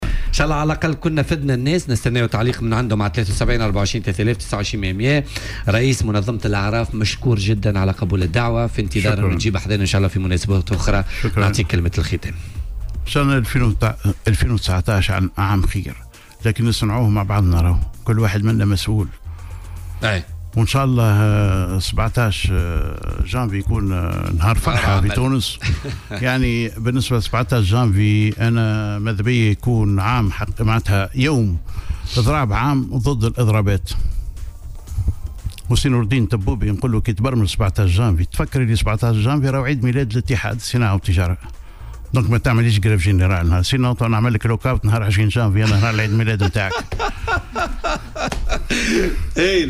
وقال ضيف "بوليتيكا" على "الجوهرة أف أم" إن الاضراب المقرّر للاتّحاد يصادف ذكرى تأسيس منظمة الأعراف يوم 17 جانفي، مؤكدا رفضه هذا الاضراب تزامنا مع هذا التاريخ.